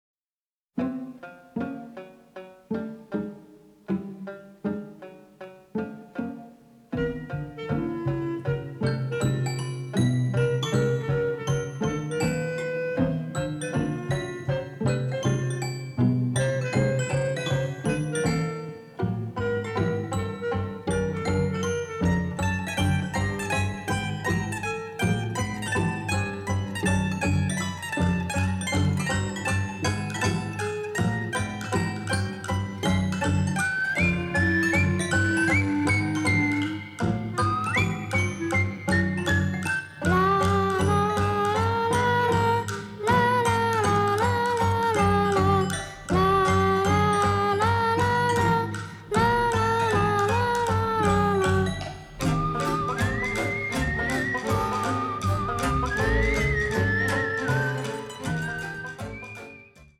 avantgarde score
It had less music, but it was all in stereo.